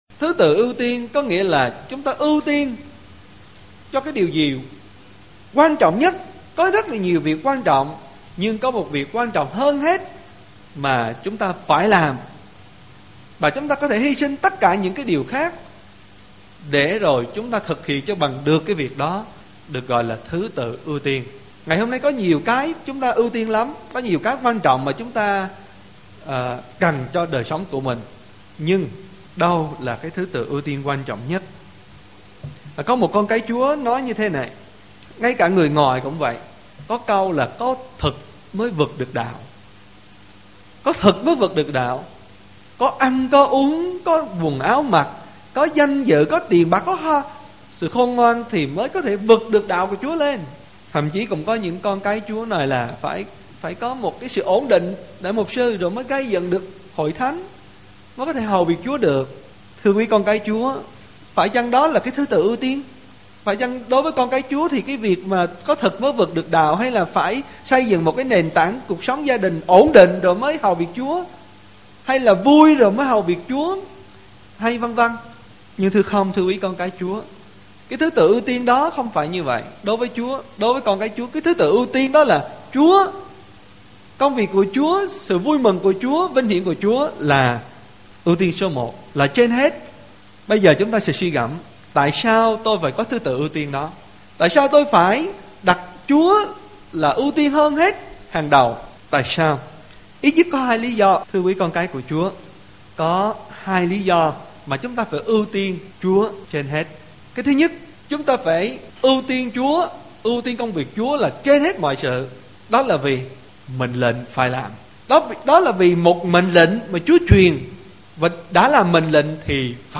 THỨ TỰ ƯU TIÊN - Bài giảng Tin Lành